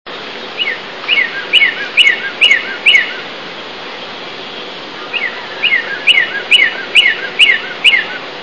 Northern Cardinal
Collegeville, PA, 3/1/00, male "whirr-a-chee" (33kb) top of leafless tree in early spring